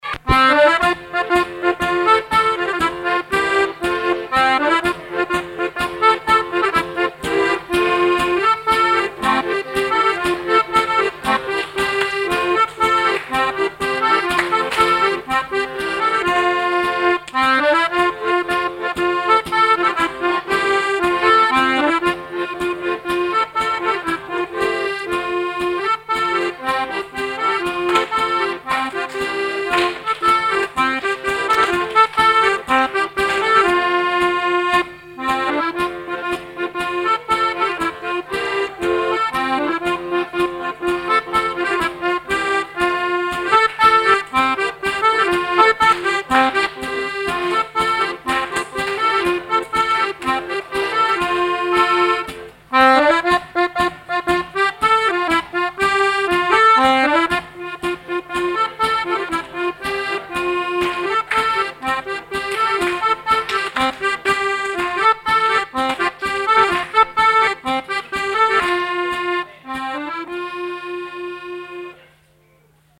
Rondes enfantines à baisers ou mariages
danse : ronde : boulangère
Genre strophique
répertoire d'airs à danser